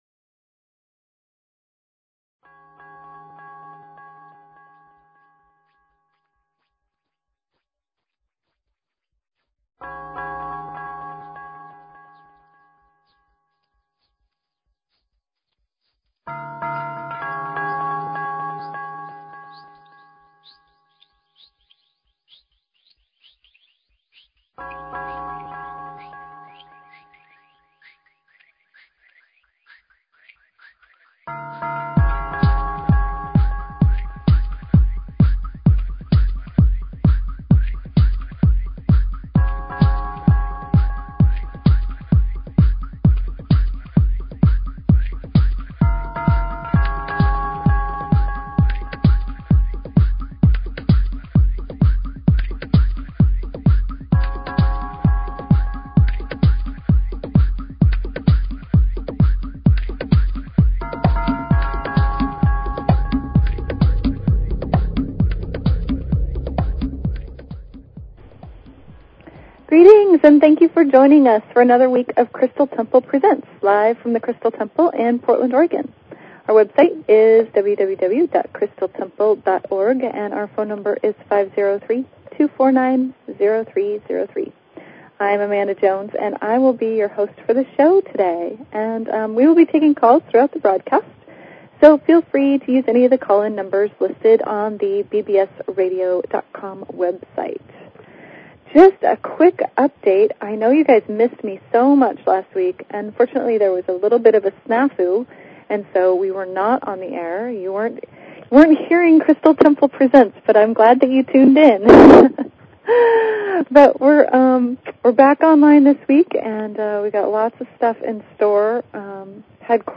Talk Show Episode, Audio Podcast, Crystal_Temple_Presents and Courtesy of BBS Radio on , show guests , about , categorized as